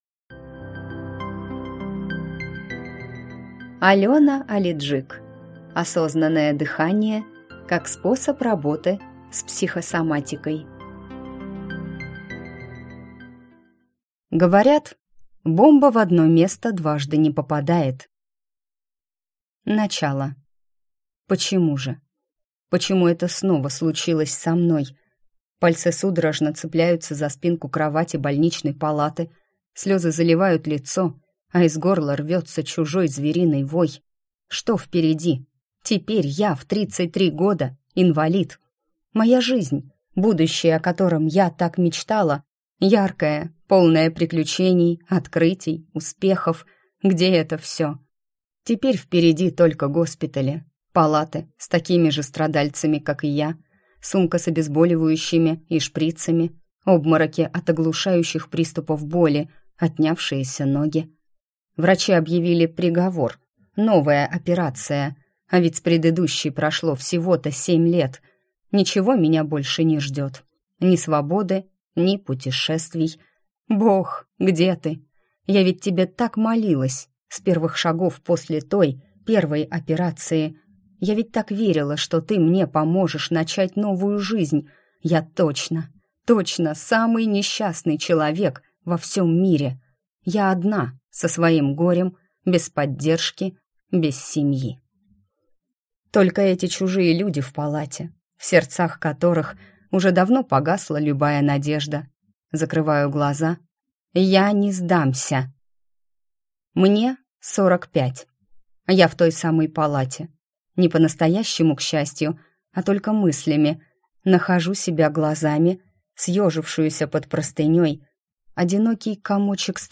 Аудиокнига Осознанное дыхание как способ работы с психосоматикой | Библиотека аудиокниг